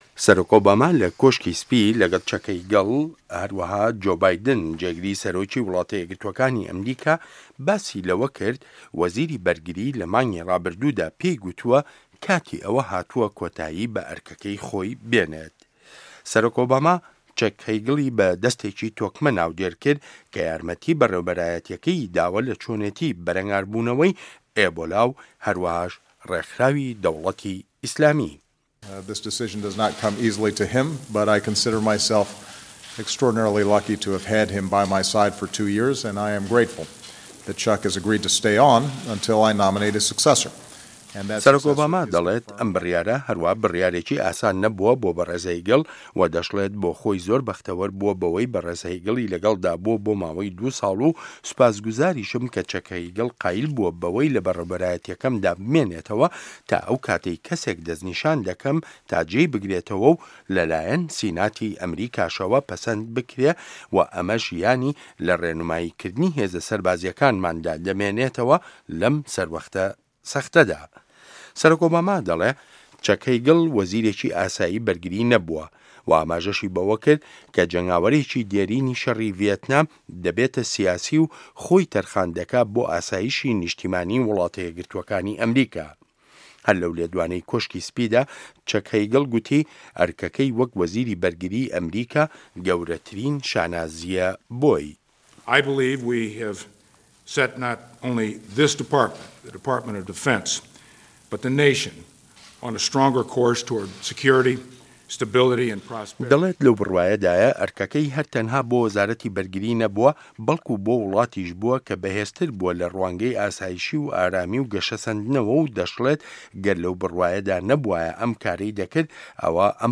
ڕاپـۆرتی سه‌رۆک ئۆباما و چه‌ک هه‌یگڵ